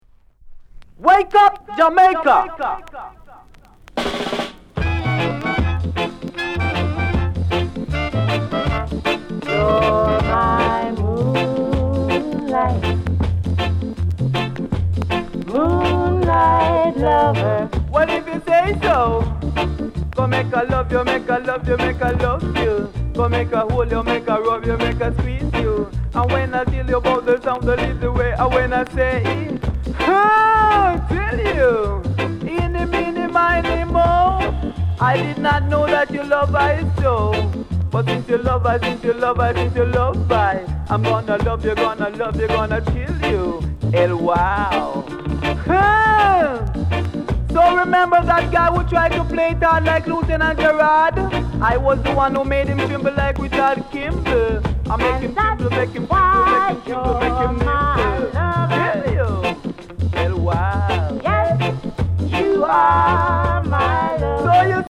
DEE JAY CUT